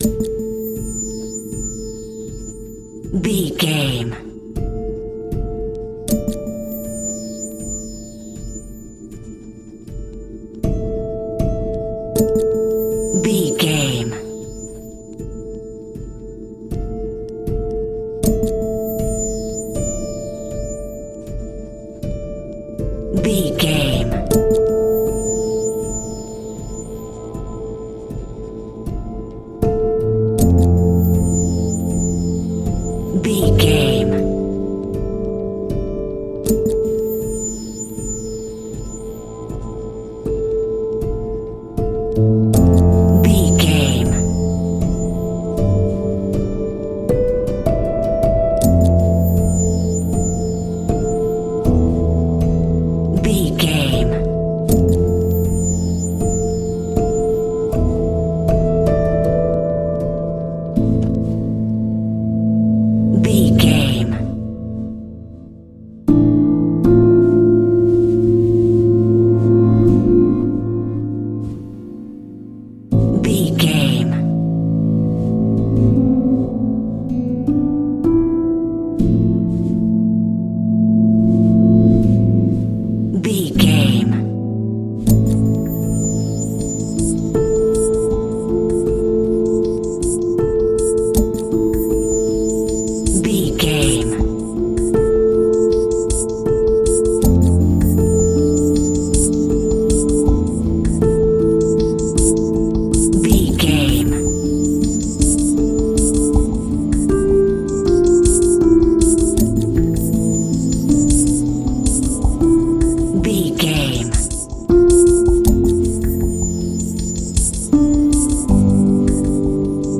Aeolian/Minor
tension
suspense
synthesiser